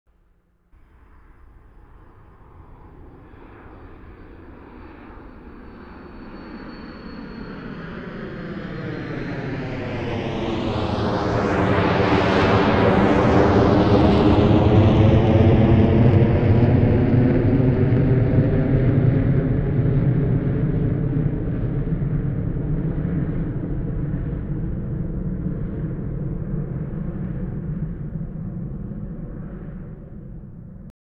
Binaural recording of a jet passing overhead